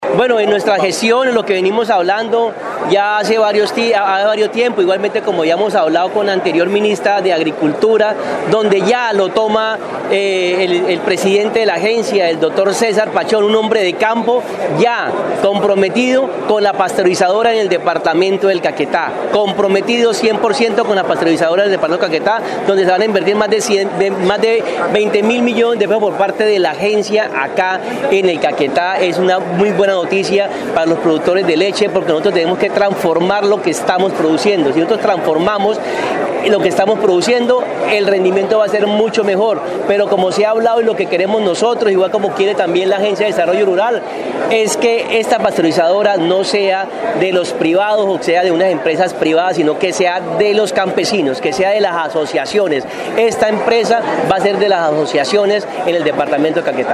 Sobre el tema el parlamentario por las víctimas de Caquetá y Huila Jhon Freddy Núñez Ramos, dijo que la planta es una necesidad teniendo en cuenta el hato ganadero existente en esta parte del sur del país.